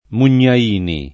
[ mun’n’a & ni ]